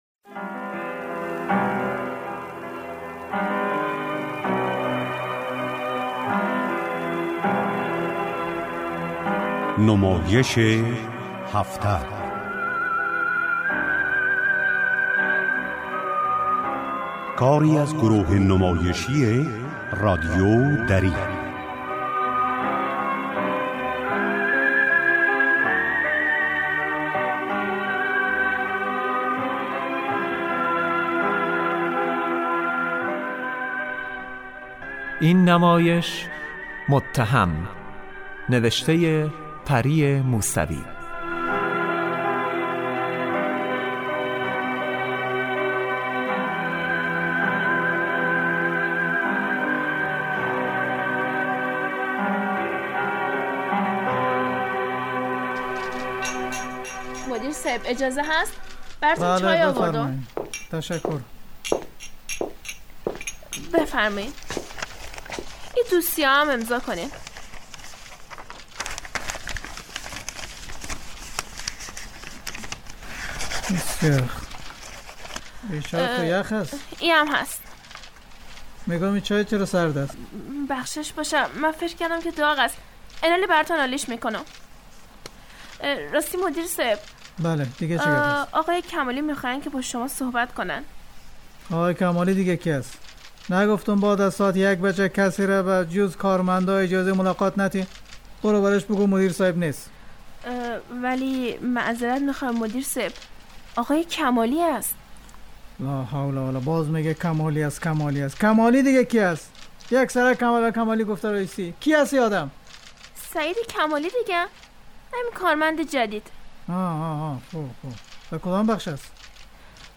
نمایش هفته